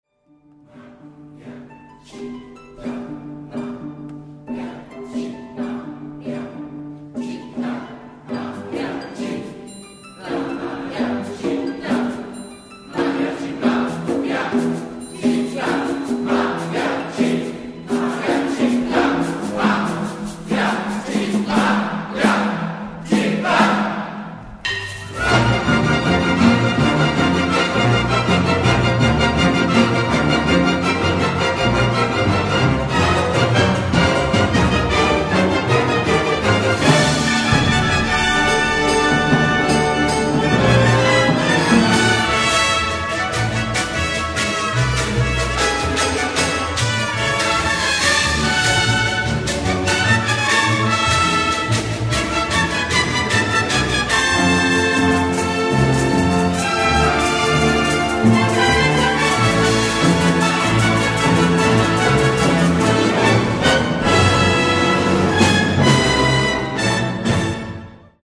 Mariachitlán es un homenaje orquestal a Jalisco, la tierra del mariachi. Los instrumentos típicos del género como la trompeta, el arpa y el violín son protagonistas en la obra, mientras los contrabajos suenan como guitarrones y las cuerdas emulan los rasgueos de las vihuelas.